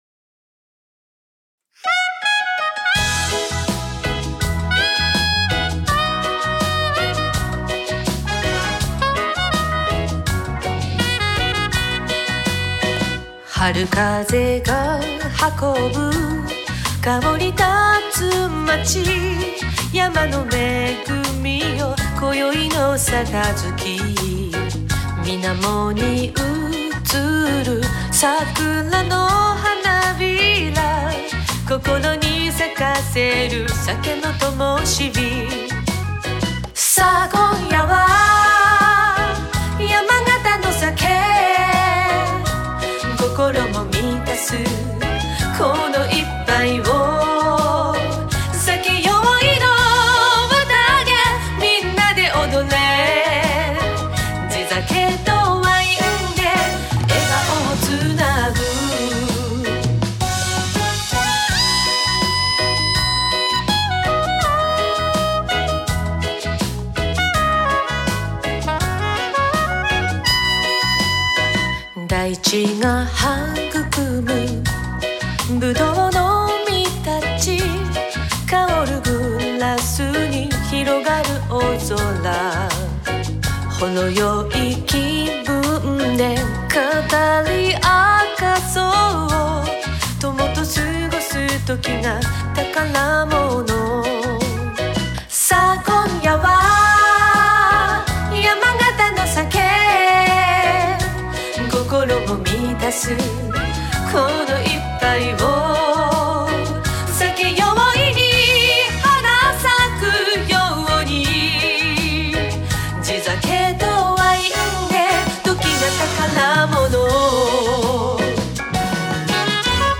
ガイドボーカルあり